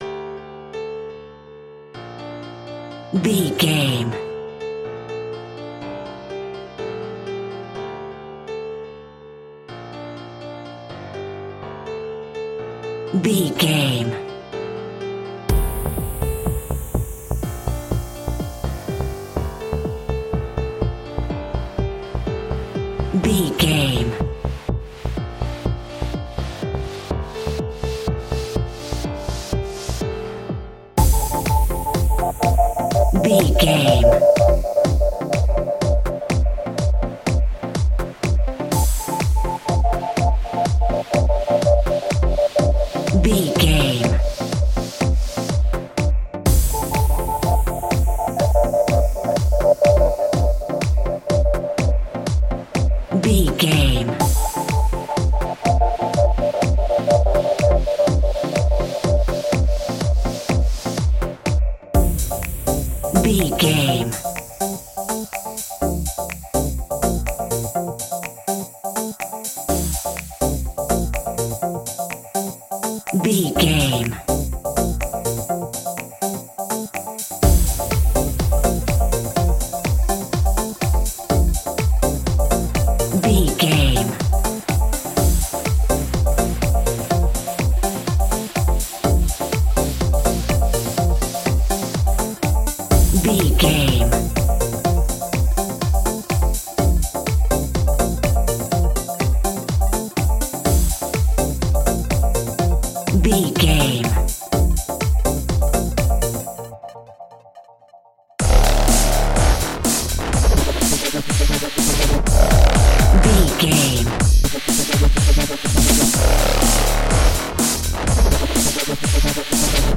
Epic / Action
Fast paced
Aeolian/Minor
aggressive
powerful
dark
driving
energetic
intense
piano
drum machine
synthesiser
breakbeat
synth drums
synth leads
synth bass